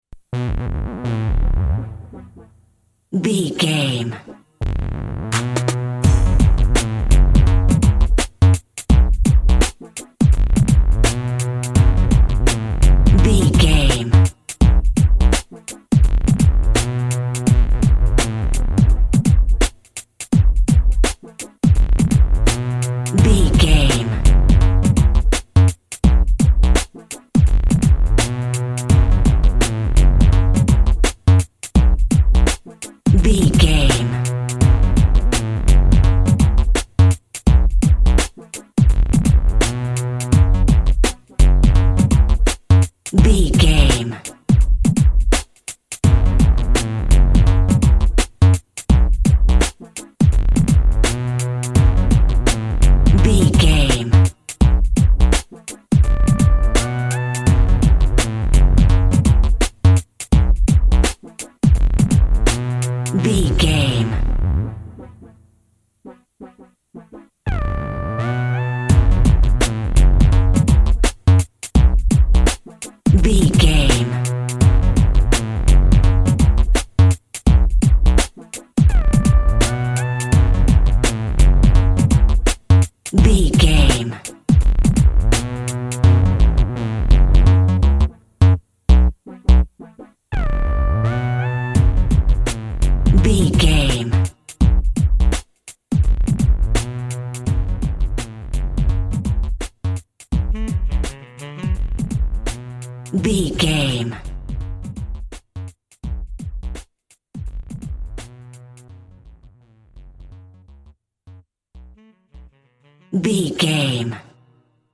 Hip Hop That's Messy.
Aeolian/Minor
synth lead
synth bass
hip hop synths
electronics